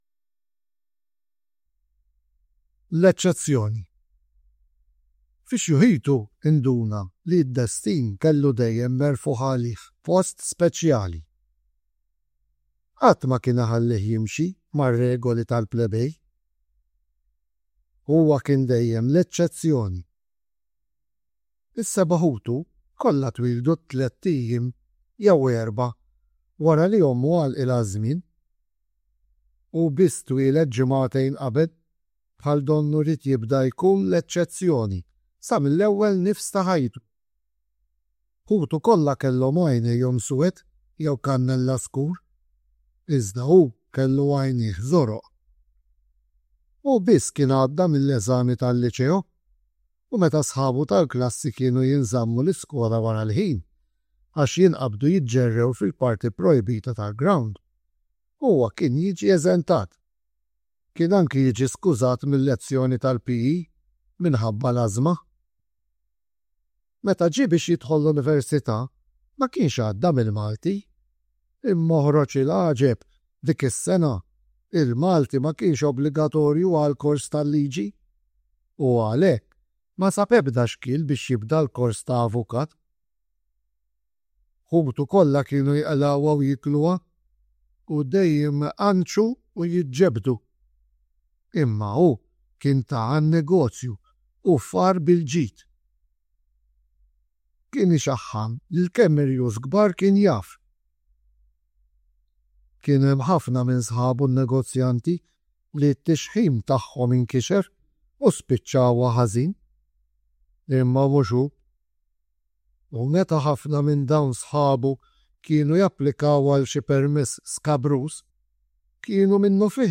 Kull taħriġ jinkludi s-silta moqrija, il-karta tat-taħriġ għall-istudenti u l-karta tal-għalliema bir-risposti.